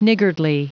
Prononciation du mot niggardly en anglais (fichier audio)
niggardly.wav